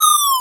Fall1.wav